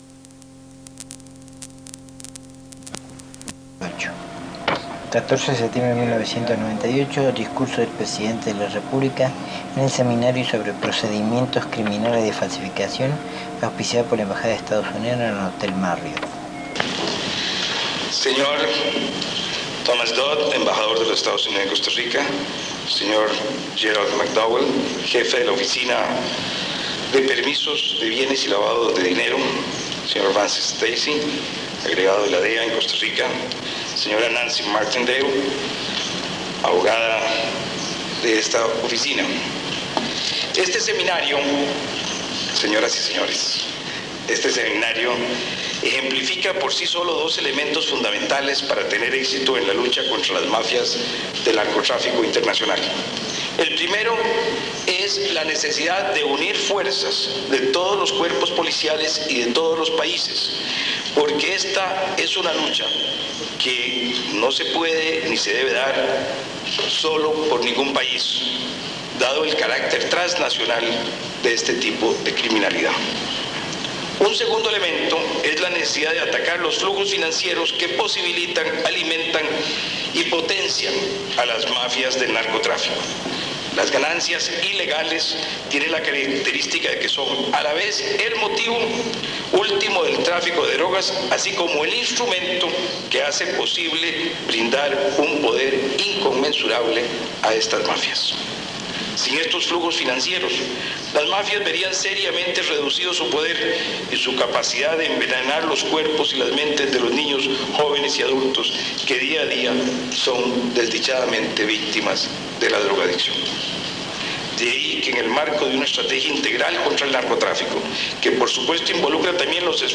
Discursos del Presidente de la República sobre seminario de procedimientos criminales de falsificación, Sesión solemne Consejo de Gobierno en Cartago, acto civico en Parque Nacional, visita a Pejibaye de Jiménez - Archivo Nacional de Costa Rica
Casette de audio